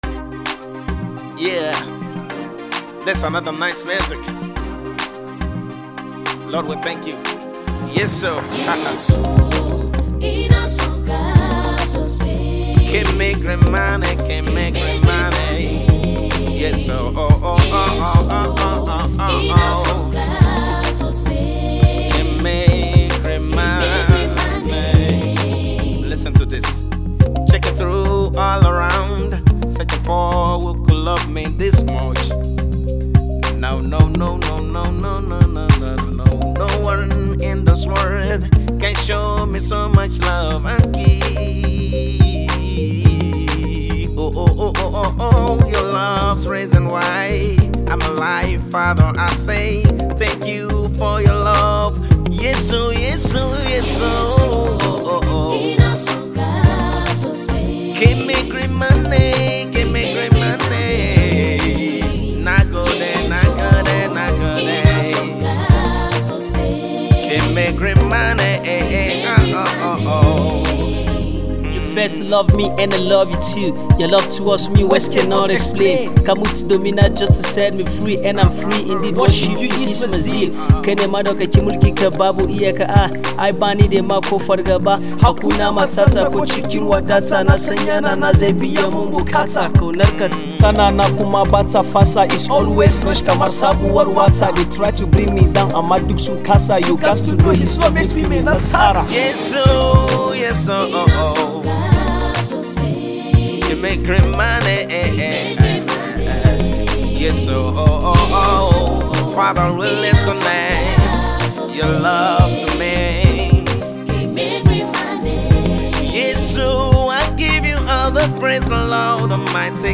thumping and hard-hitting Afro Hip-Hop tune
Christian rapper